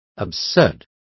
Complete with pronunciation of the translation of absurd.